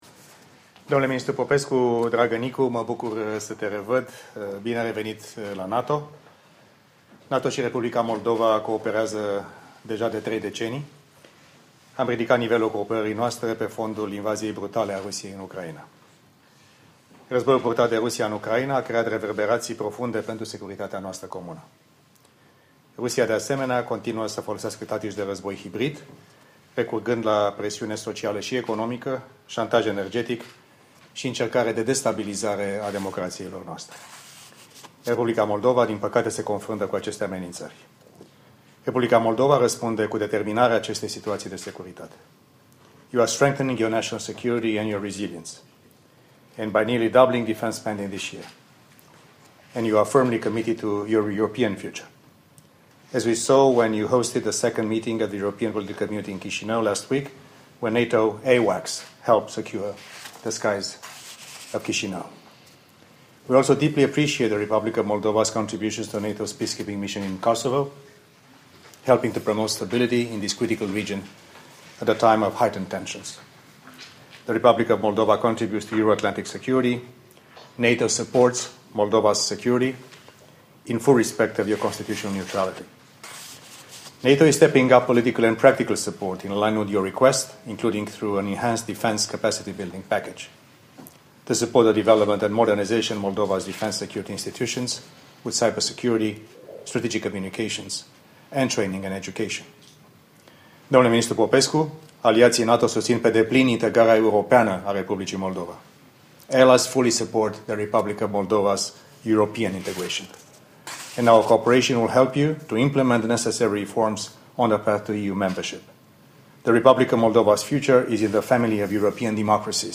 Statement
by NATO Deputy Secretary General Mircea Geoană with the Foreign Minister of the Republic of Moldova, Nicu Popescu